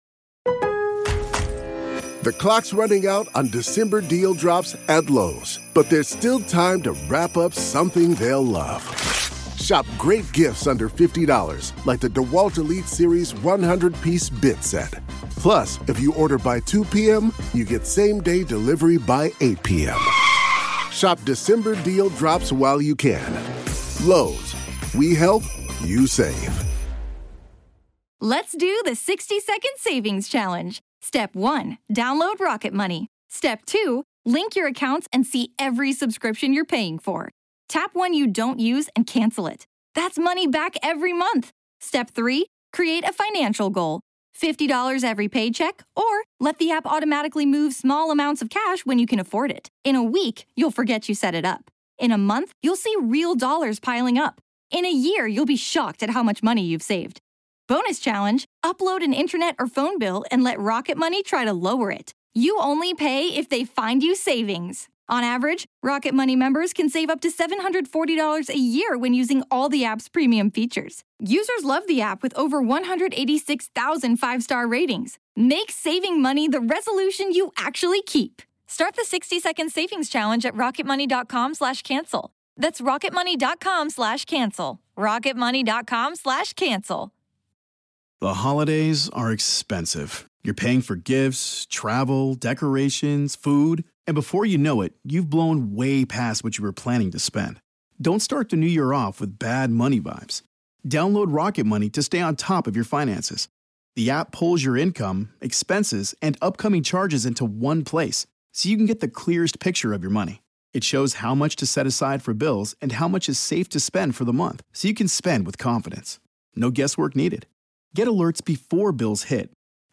Today, we will talk about the investigation's current status with a private investigator.